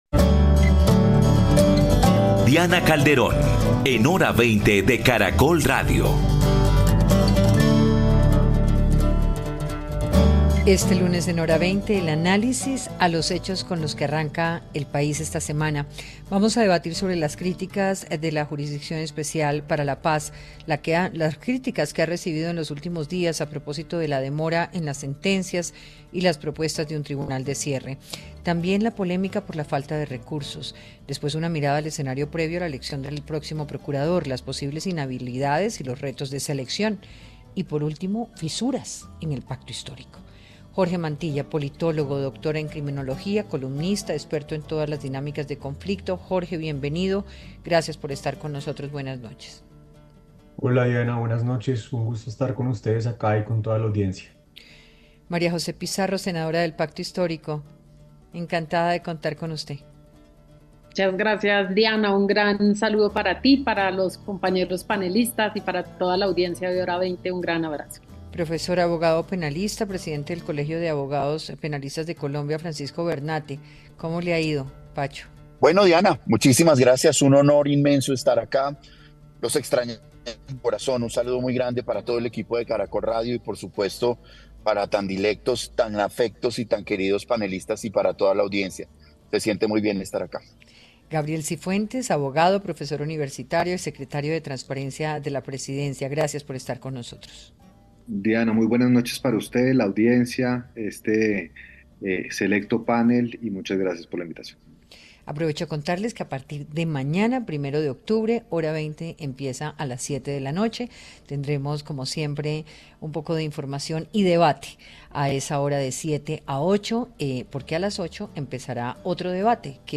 Panelistas consideran que la labor del tribunal no es fácil, que se deben conocer pronto algunas sentencias, pero advierten sobre los avances en términos de reconciliación.